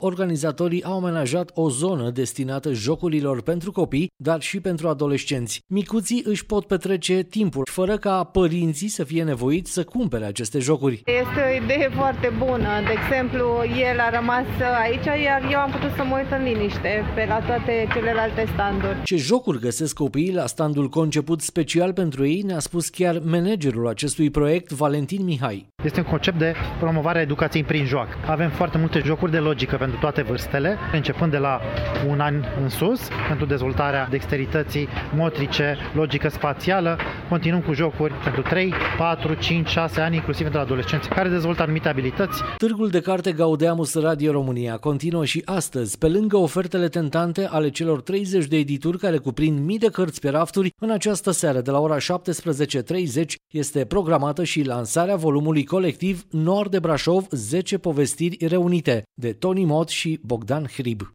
a trecut pragul târgului şi a aflat ce găsesc acolo copiii: